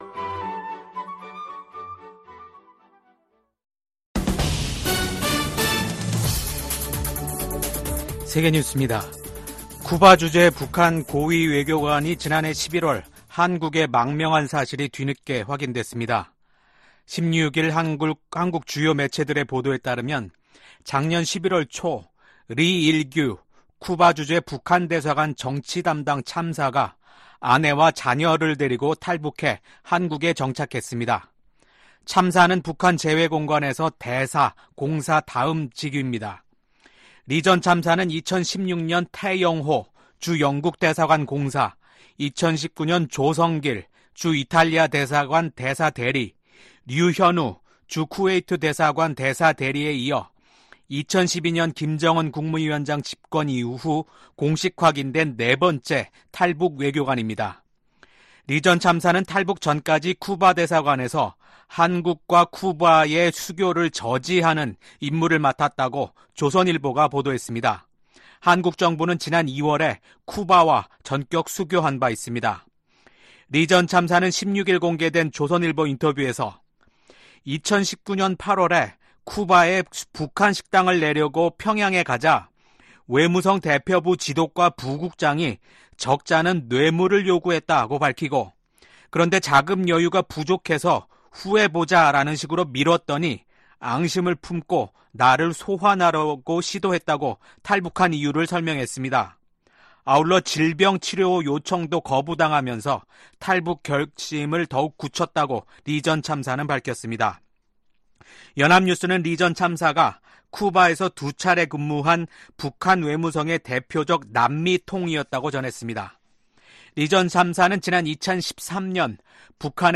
VOA 한국어 아침 뉴스 프로그램 '워싱턴 뉴스 광장' 2024년 7월 17일 방송입니다. 도널드 트럼프 전 미국 대통령이 공화당 대선 후보로 공식 지명됐습니다.